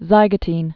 (zīgə-tēn)